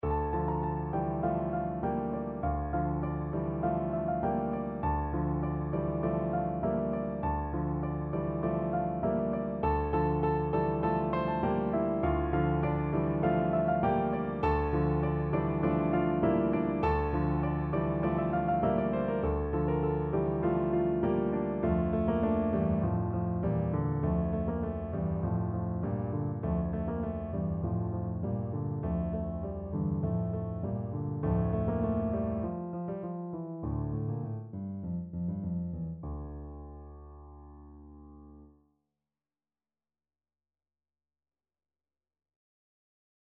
Piano version
No parts available for this pieces as it is for solo piano.
Allegro (View more music marked Allegro)
4/4 (View more 4/4 Music)
Classical (View more Classical Piano Music)